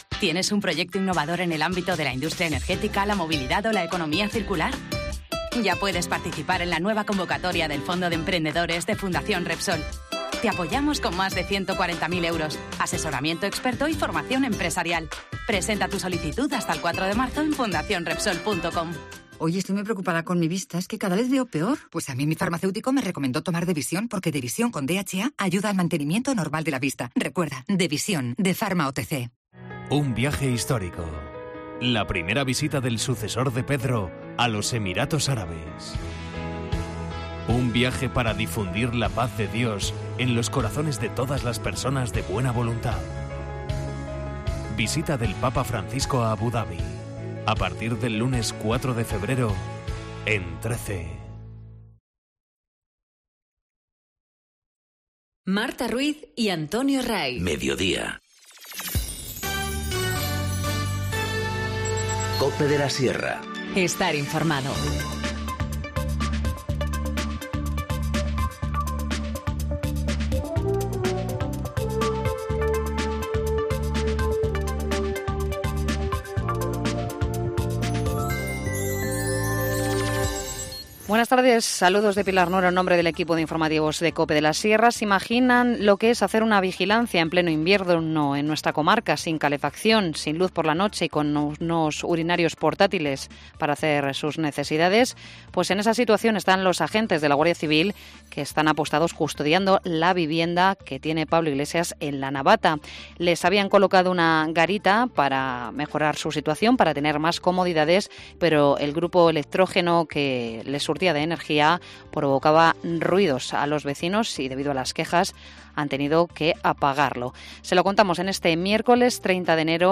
Informativo Mediodía 30 enero- 14:20h